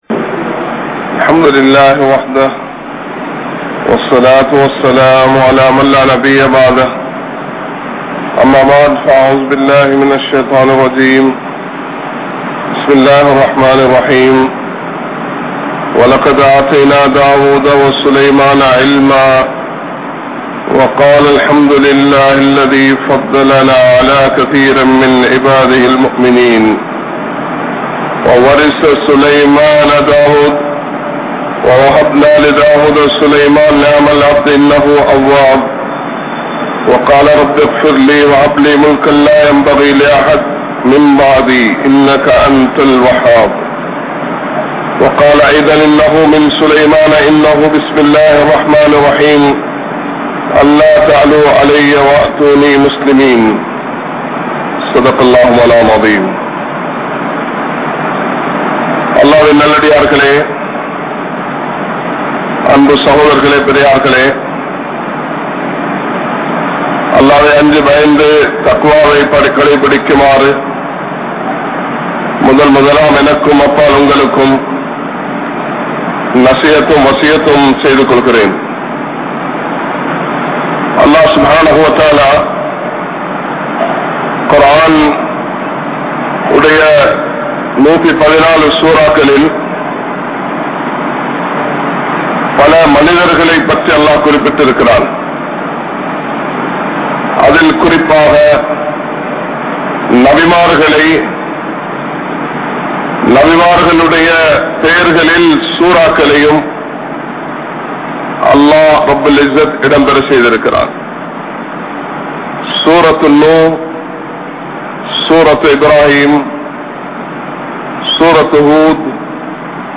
PuththiSaali Yaar? (புத்திசாலி யார்?) | Audio Bayans | All Ceylon Muslim Youth Community | Addalaichenai
Kollupitty Jumua Masjith